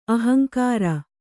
♪ ahaŋkāra